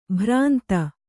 ♪ bhrānta